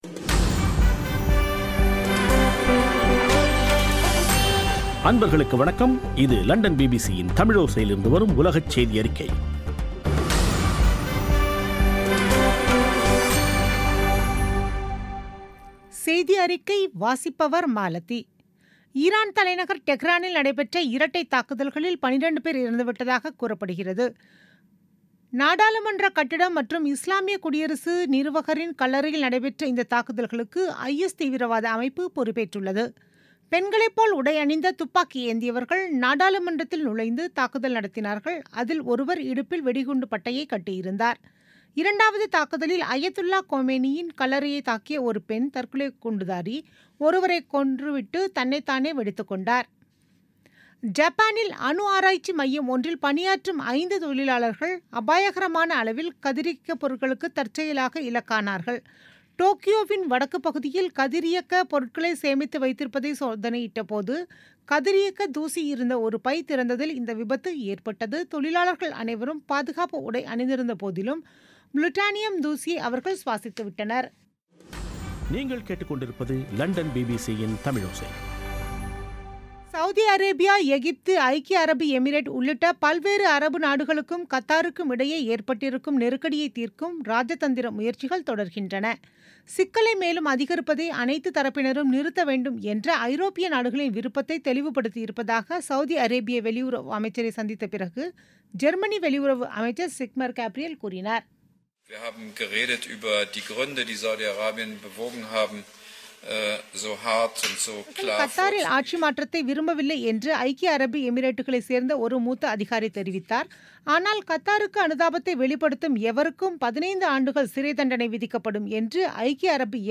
பிபிசி தமிழோசை செய்தியறிக்கை (07/06/2017)